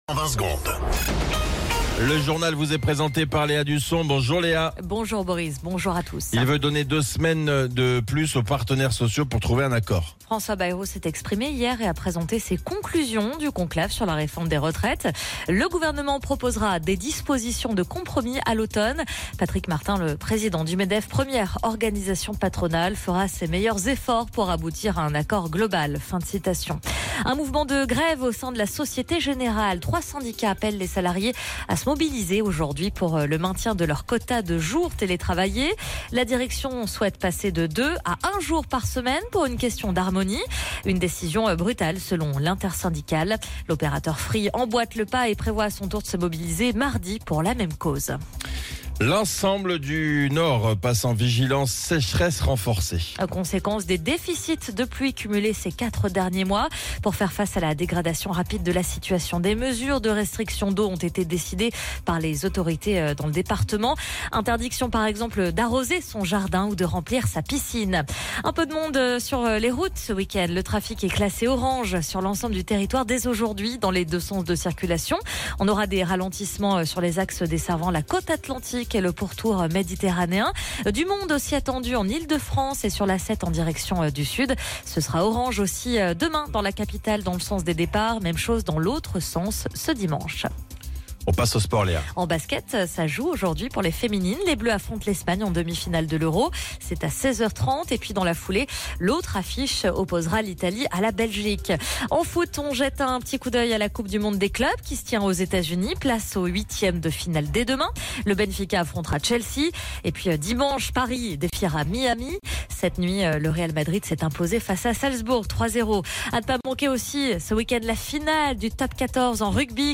Flash Info National 27 Juin 2025 Du 27/06/2025 à 07h10 .